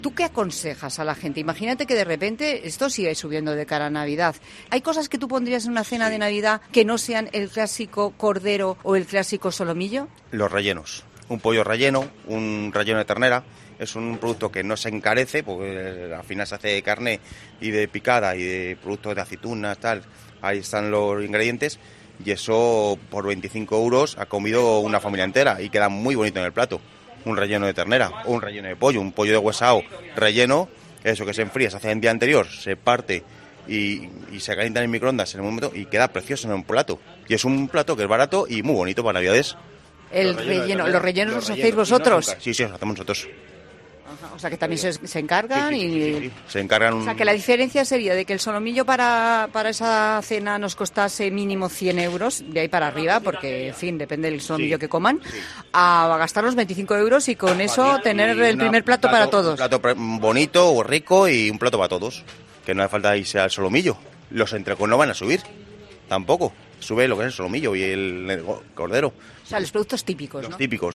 Un carnicero desvela la cena perfecta para estas Navidades: “Es muy barato y queda muy bonito”